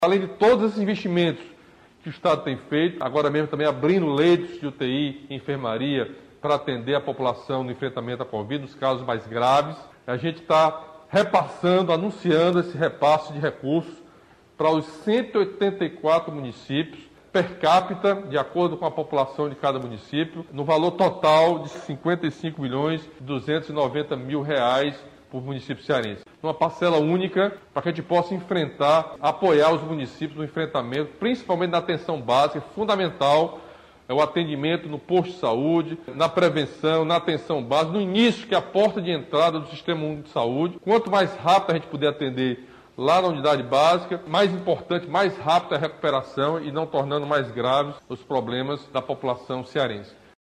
O anúncio foi feito na manhã desta quinta-feira (20) pelo governador Camilo Santana.